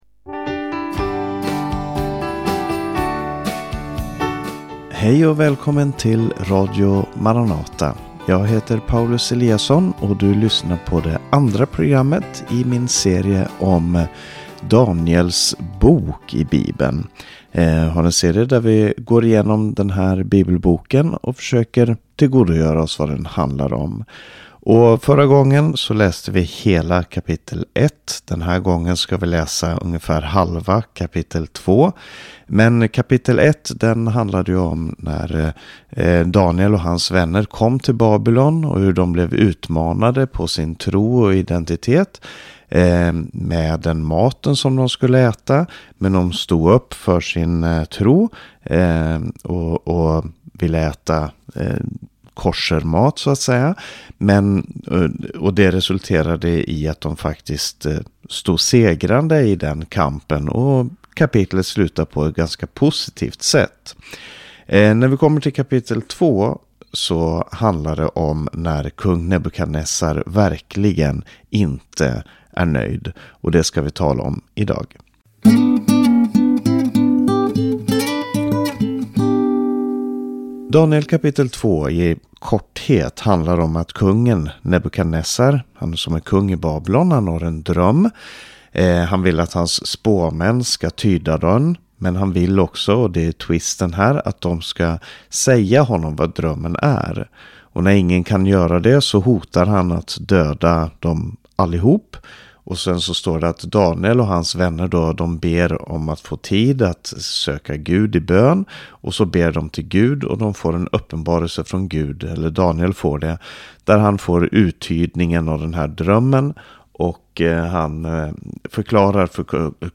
undervisar från Daniels bok. När kung Nebukadnessar har en konstig dröm - då ska huvuden rulla. Hur använder världens härskare sin makt - och hur använder Gud sin makt?